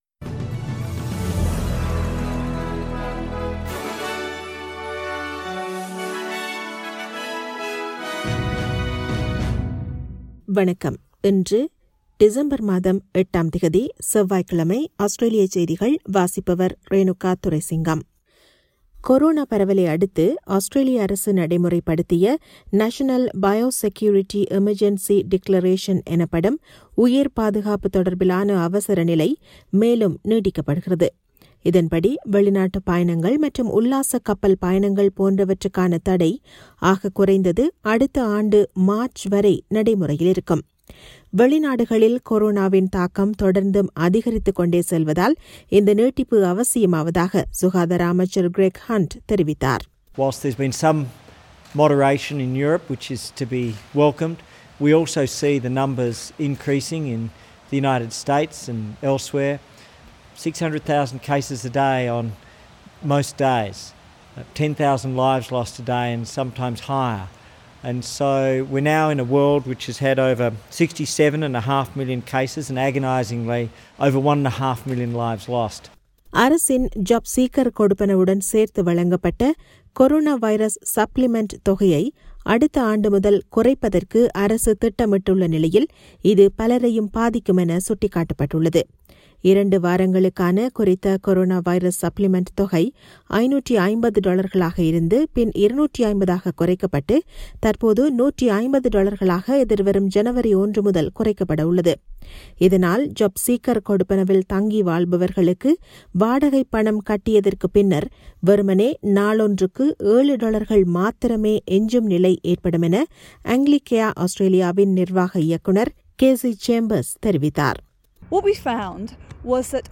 Australian news bulletin for Tuesday 08 December 2020.